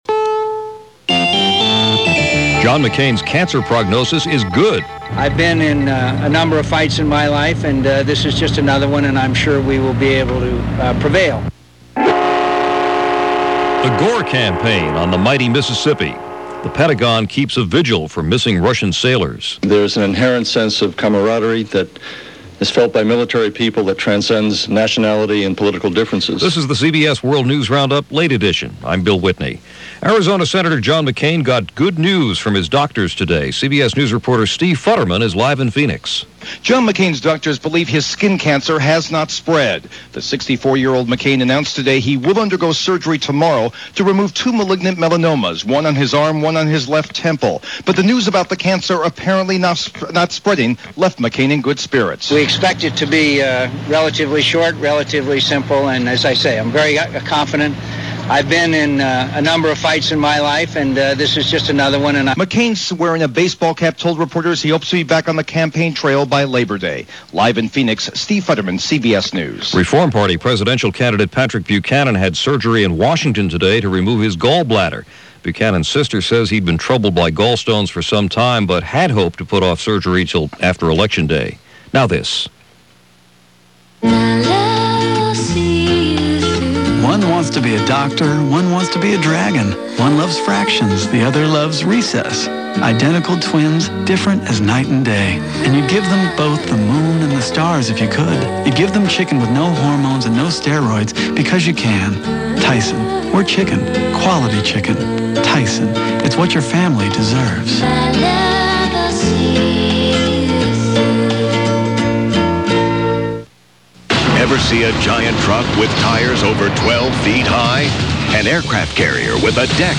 And that’s a small slice of what went on this August 18th in 2000, as presented by The CBS World News Roundup-Late Edition.